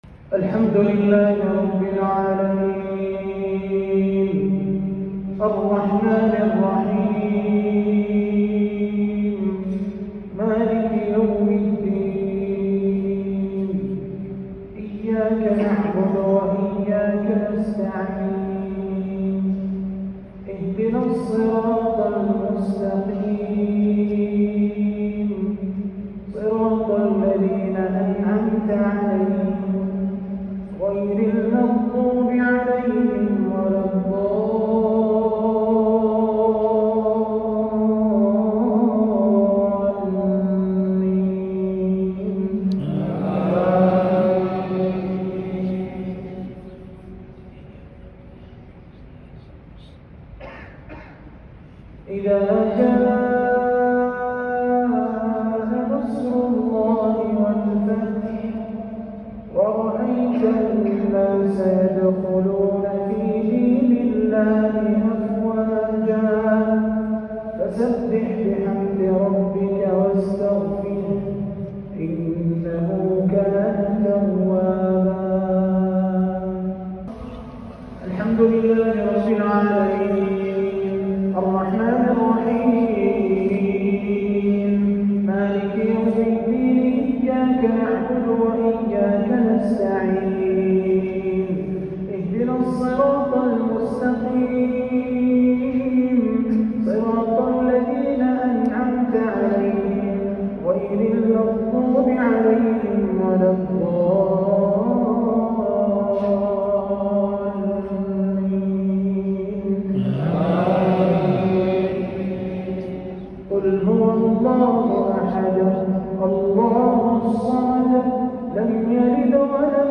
تلاوة رائعة لسور الفاتحة و النصر والاخلاص
بجامع الراجحي بحي الجزيرة بالرياض